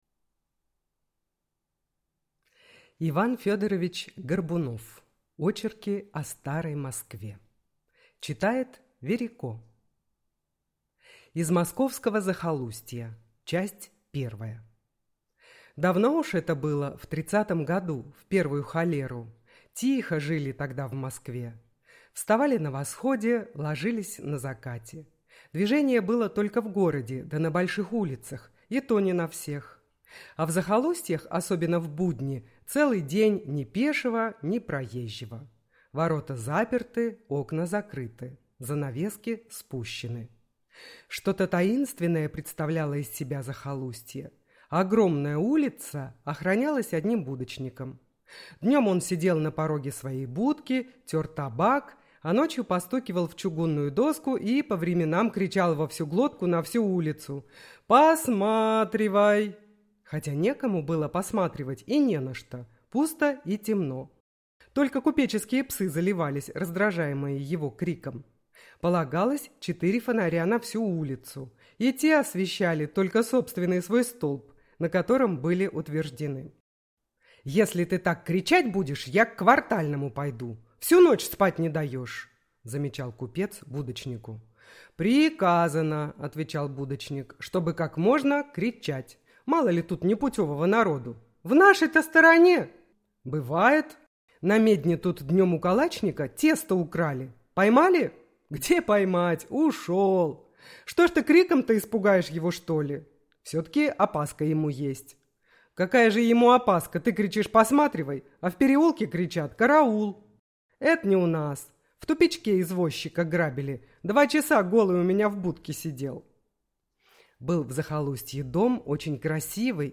Аудиокнига Очерки о старой Москве | Библиотека аудиокниг